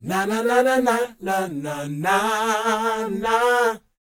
NA-NA A BD-R.wav